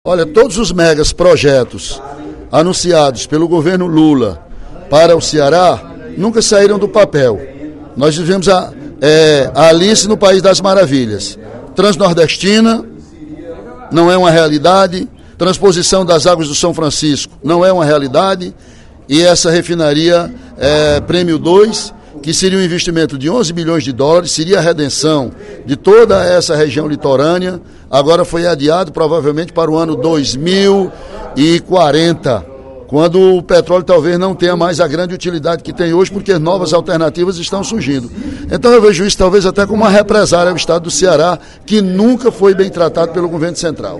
O deputado Ely Aguiar (PSDC) afirmou, nesta terça-feira (26/06) em plenário, que a ideologia está sendo esquecida na atual pré-campanha eleitoral.